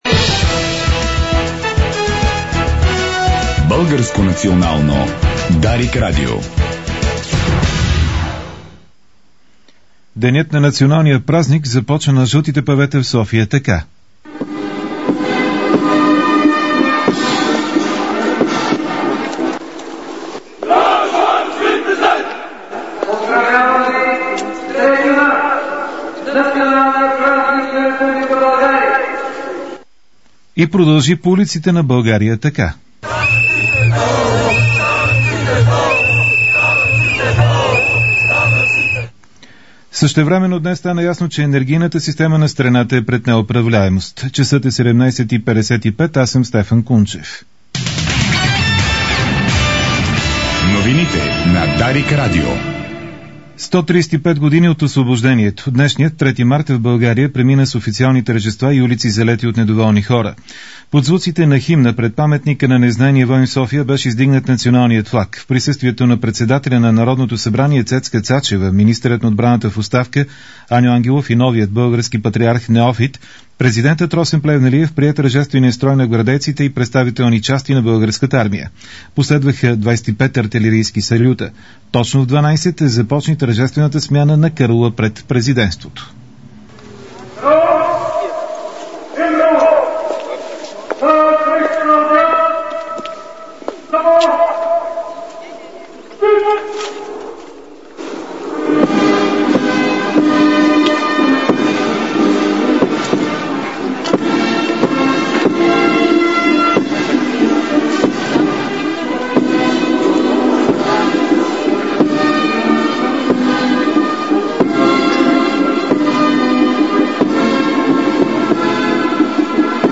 Обзорна информационна емисия